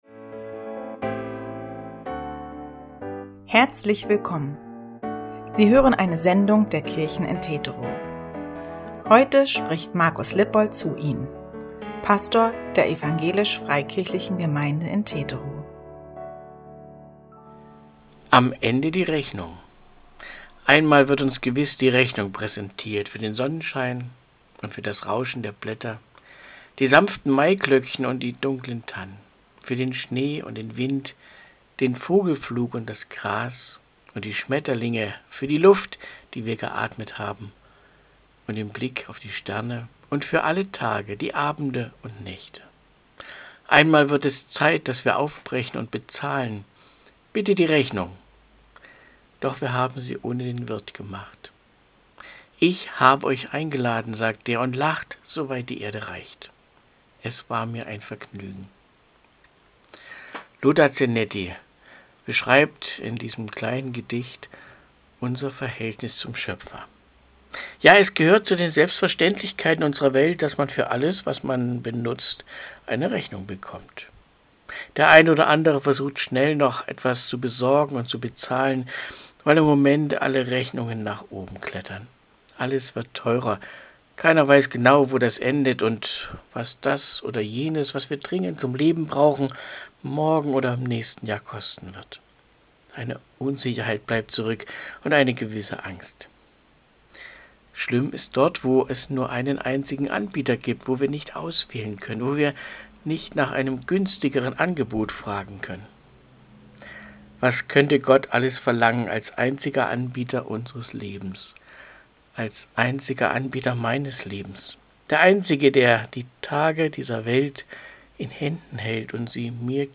Audio-Andacht 14.08.2022